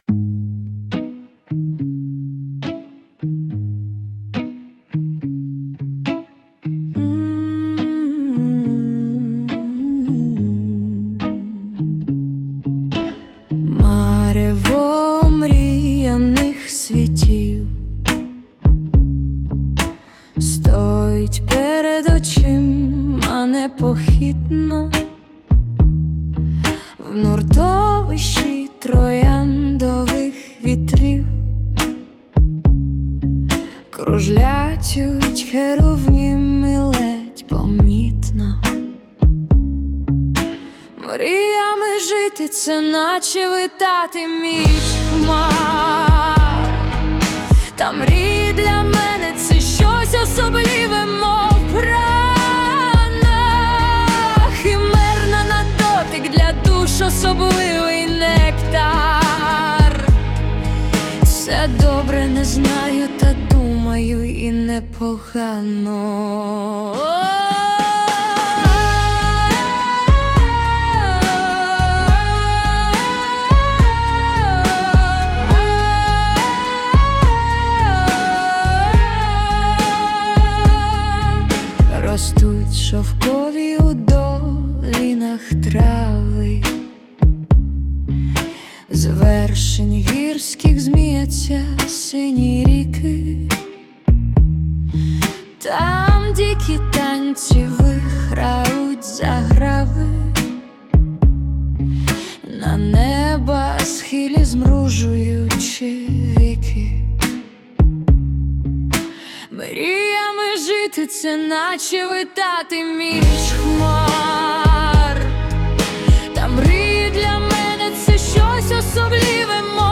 текст авторський...музика і виконання - ШІ
СТИЛЬОВІ ЖАНРИ: Ліричний